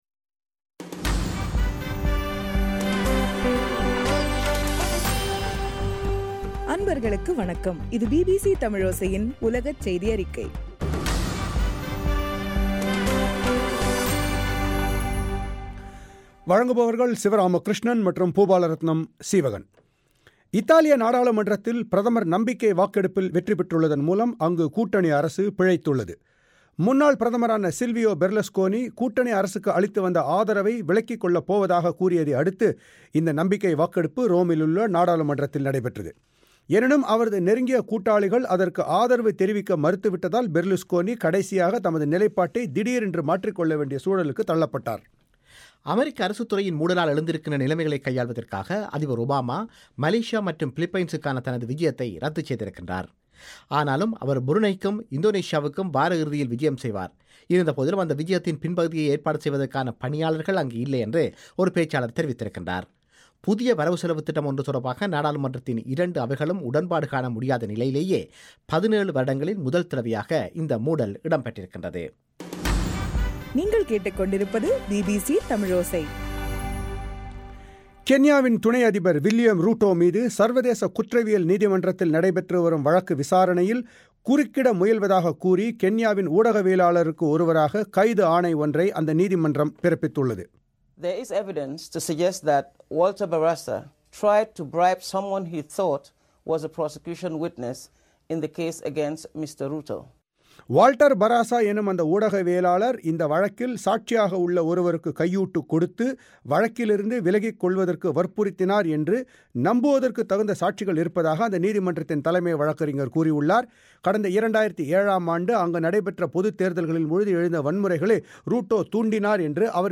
அக்டோபர் 2 பிபிசி தமிழோசை உலகச் செய்தி அறிக்கை